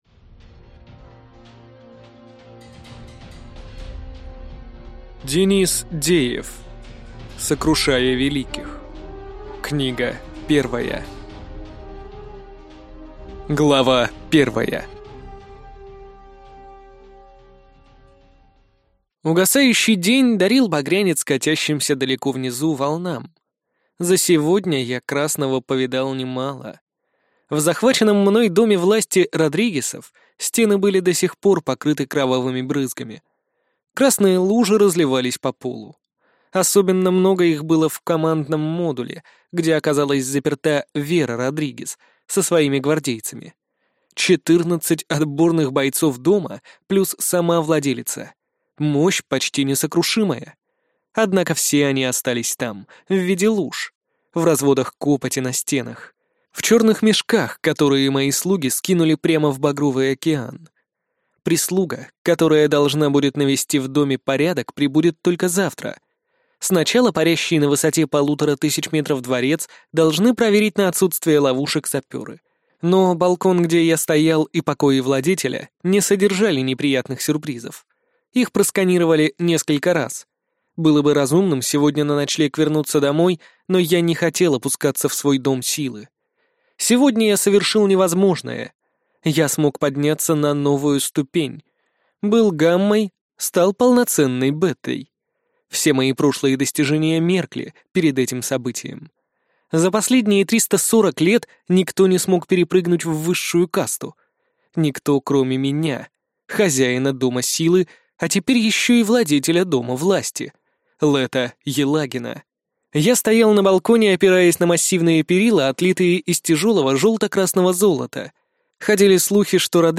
Аудиокнига Сокрушая великих. Книга 1 | Библиотека аудиокниг